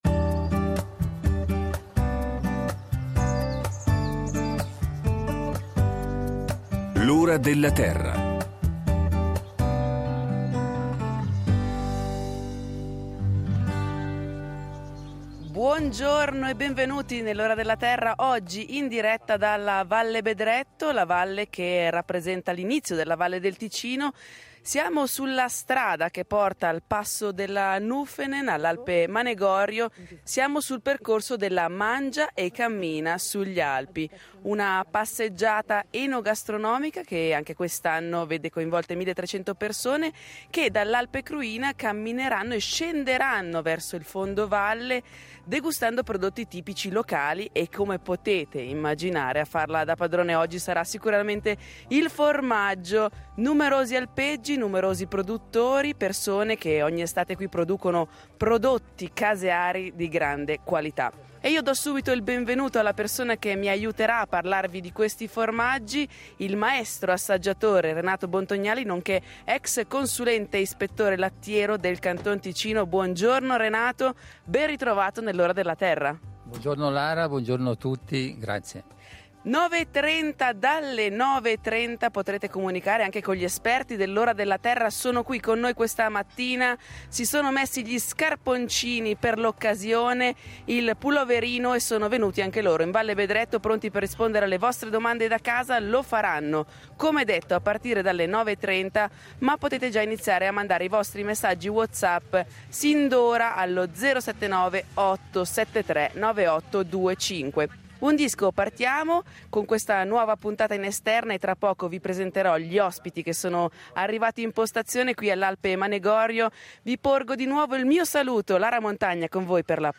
Una giornata dedicata alla scoperta della Valle Bedretto e dei suoi alpeggi, dove i partecipanti possono degustare in compagnia i genuini sapori locali. Dalle 07:30 alle 17:00 centinaia di persone si riversano sui sentieri della Valle e Rete Uno è presente per riferire al pubblico di questa manifestazione che ogni anno regala prelibatezze per ogni gusto.